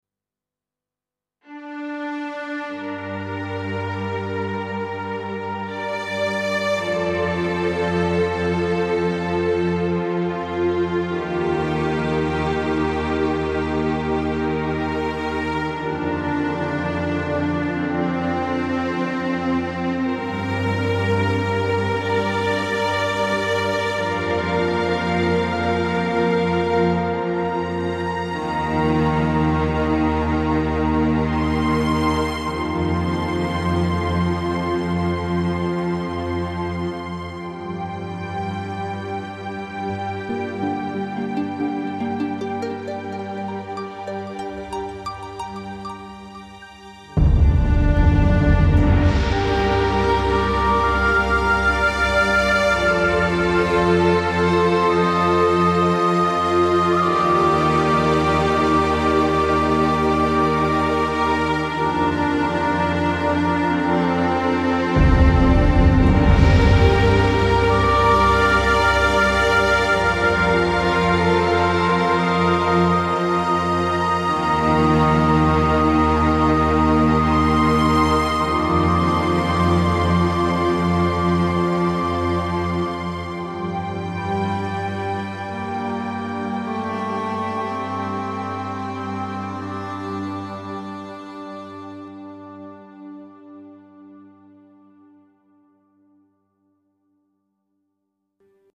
Ein Bespiel für die schöne Hintergrundmusik des Spiels.